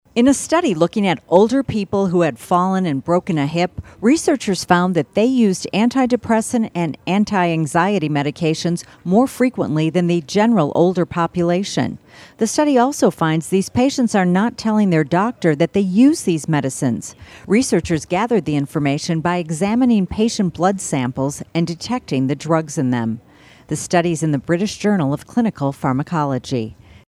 Health Reporter